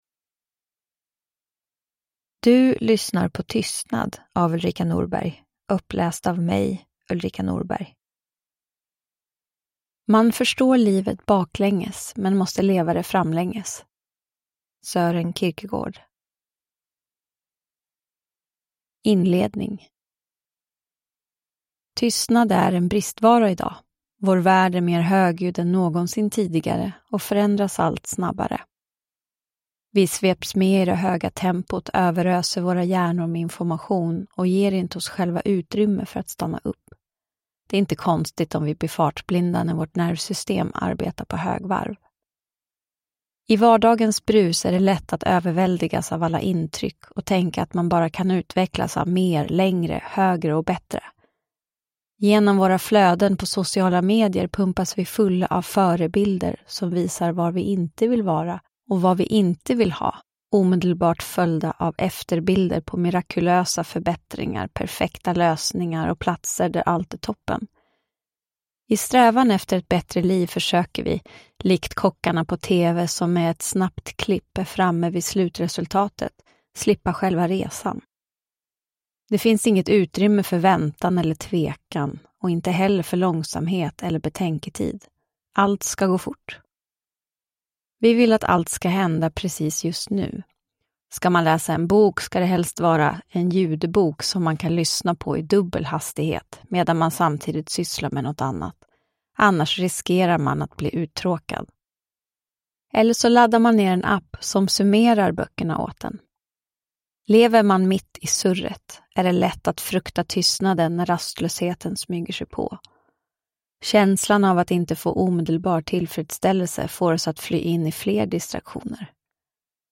Tystnad : Värdet av att minska bruset – Ljudbok